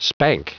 Prononciation du mot spank en anglais (fichier audio)
Prononciation du mot : spank